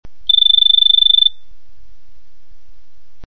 Telefono che squilla con cicalino elettronico
Suono elettronico con cicalino molto particolare. Loopabile.